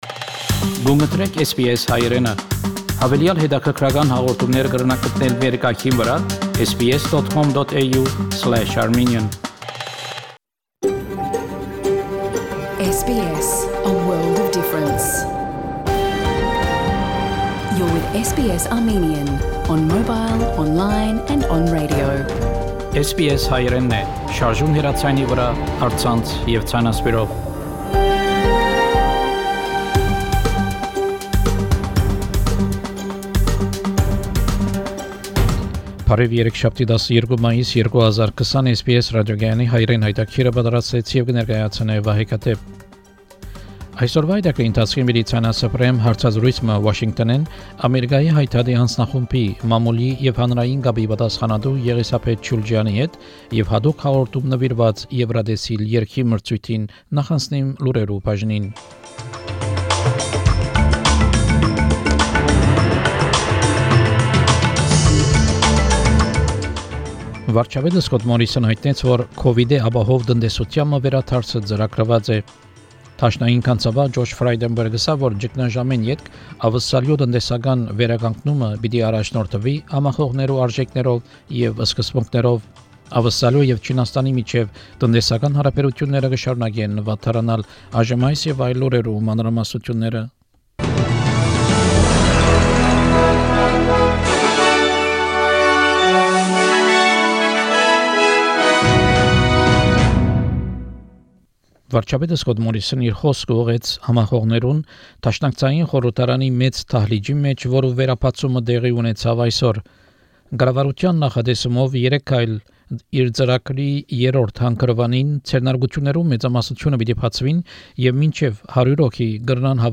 SBS Armenian news bulletin – 12 May 2020